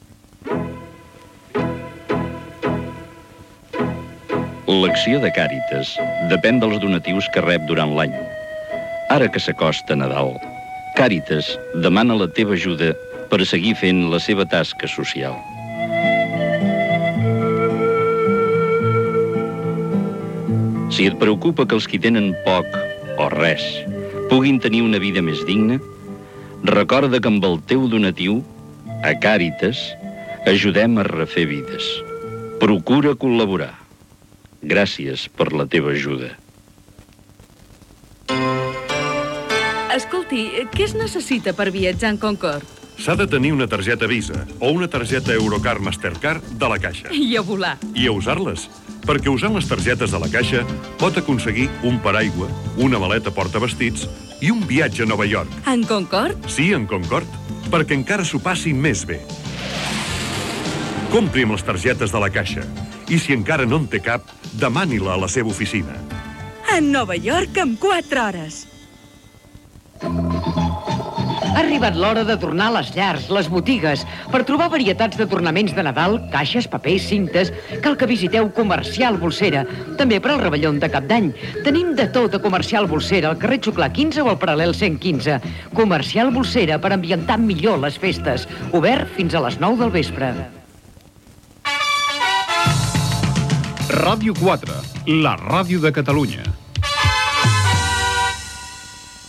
Publicitat i indicatiu de l'emissora